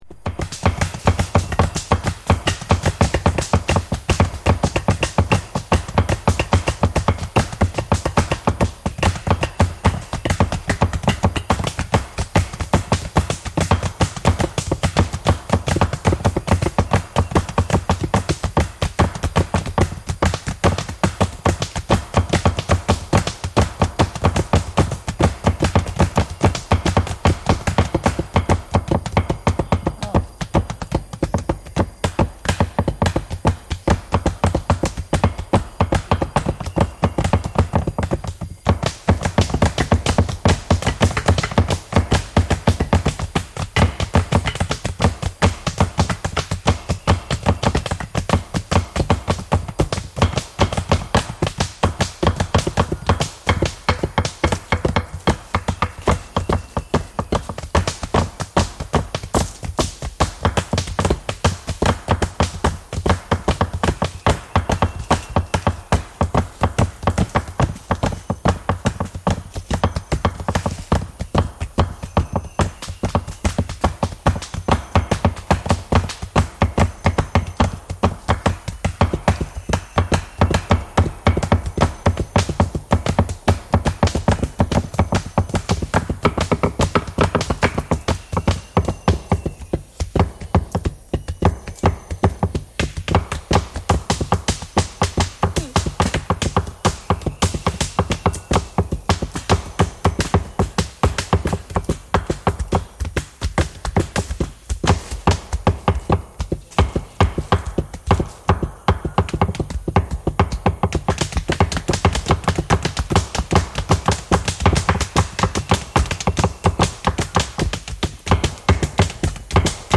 Gooma (tree drum) played with sticks
cassette tape and digital audio tape recordings of Bayaka music and soundscapes